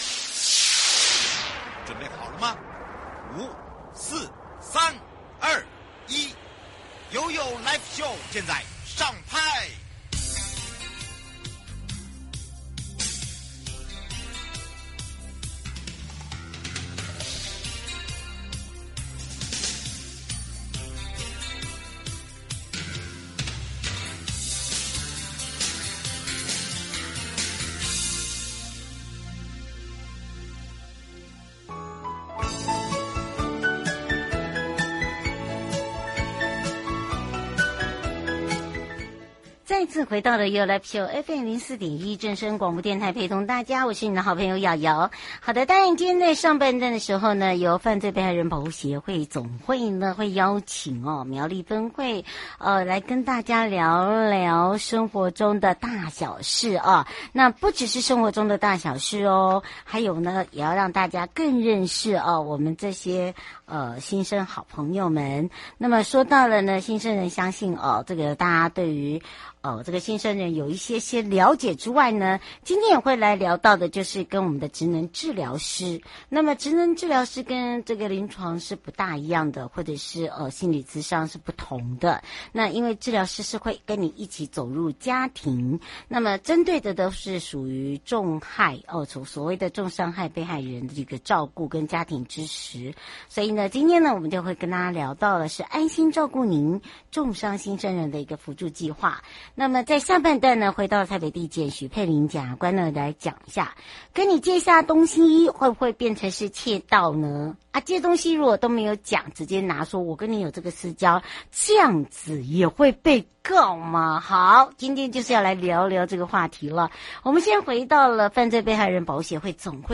受訪者： 犯罪被害人保護協會總會